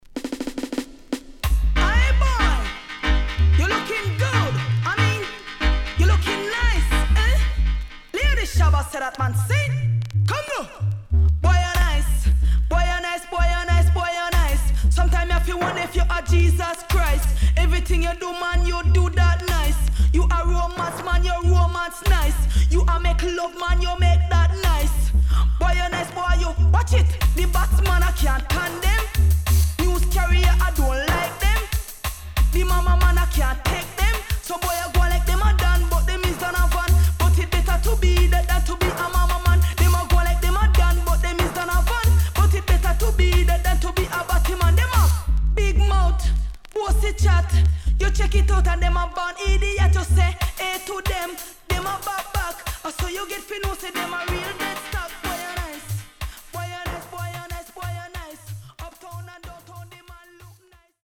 HOME > DISCO45 [DANCEHALL]
SIDE A:所々チリノイズがあり、少しプチノイズ入ります。